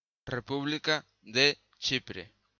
pronunciación (axuda · info)) (en grego: Κυπριακή Δημοκρατία, en turco: Kıbrıs Cumhuriyeti) é unha república que abrangue o territorio da illa homónima, situada ó leste do mar Mediterráneo, 113 km ao sur de Turquía, 120 km ao oeste de Siria e 150 km ao leste da illa grega de Kastellorizo.
Gl-República_de_Chipre.ogg